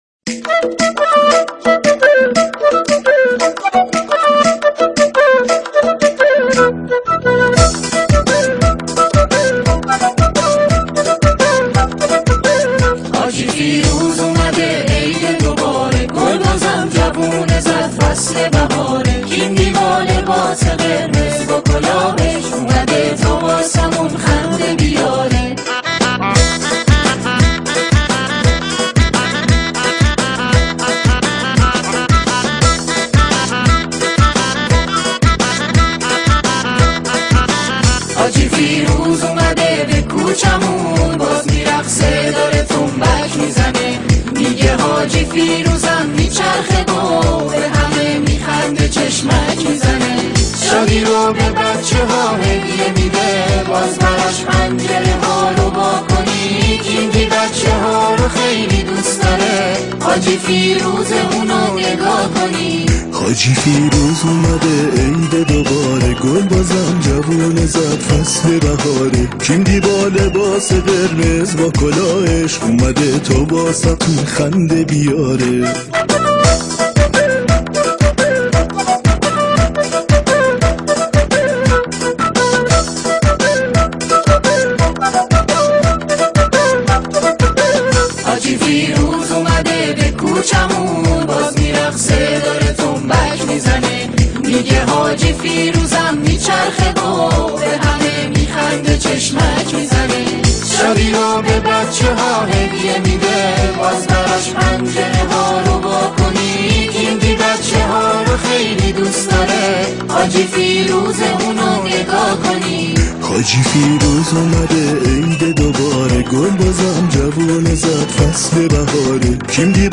آهنگ کودکانه